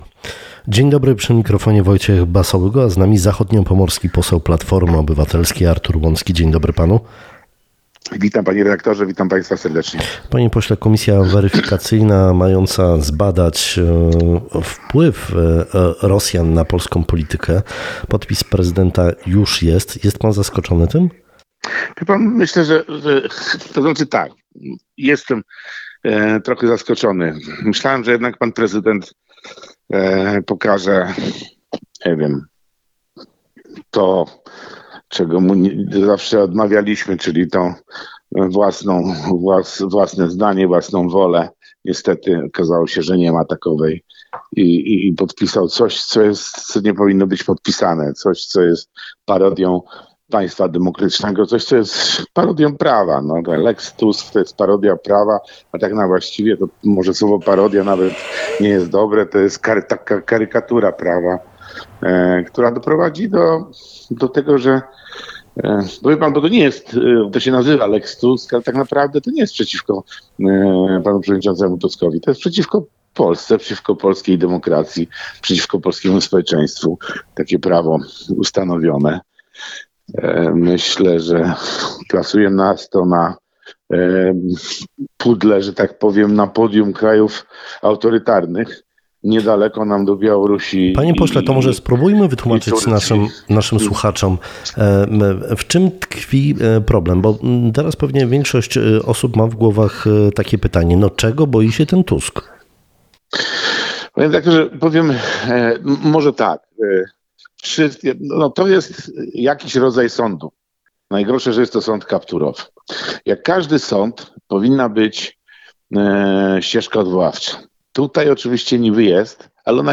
Ustawa o powołaniu komisji do spraw badania wpływów rosyjskich przede wszystkim jest niekonstytucyjna, a po drugie – będzie wykorzystywana do zwalczania przeciwników politycznych PiS-u – tak uważa zachodniopomorski poseł PO Artur Łącki, nasz dzisiejszy gość Rozmowy Dnia.